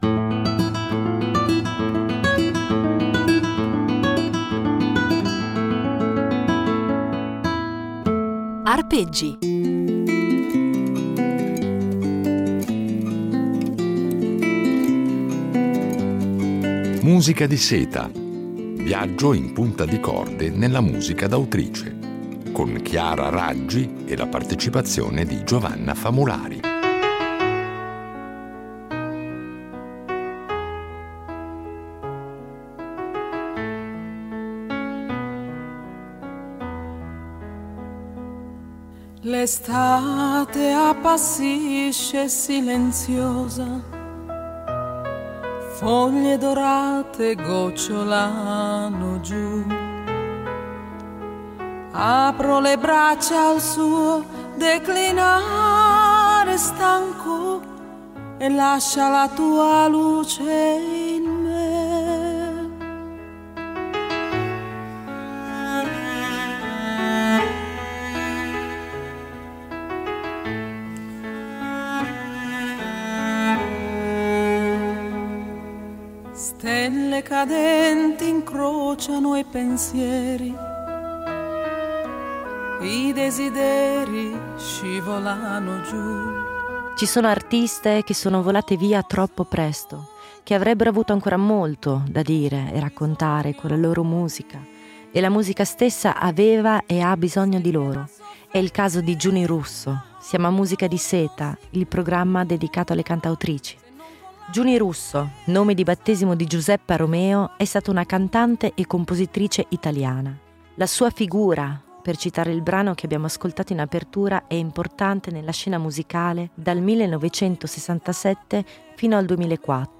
Una serie di Arpeggi impreziosita dalle riletture originali di un duo, ancora inedito, con la violoncellista